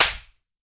slap2.ogg